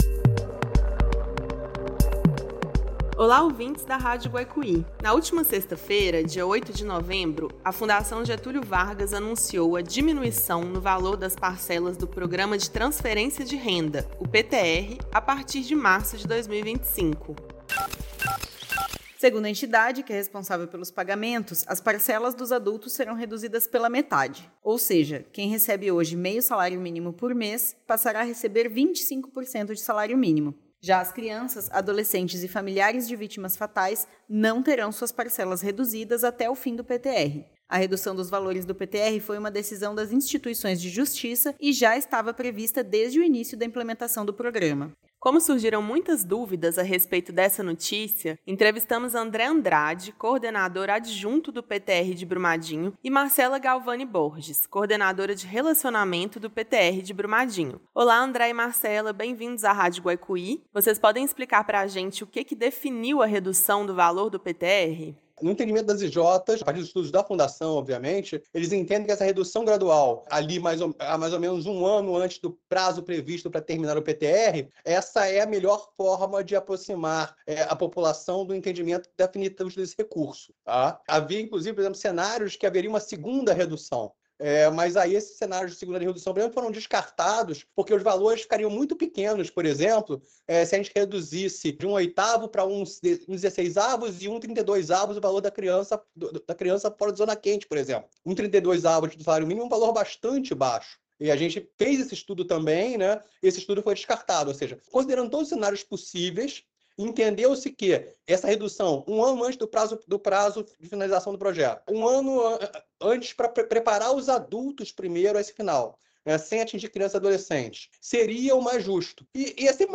Radio-Guaicuy-entrevista-FGV-sobre-reducao-do-PTR.mp3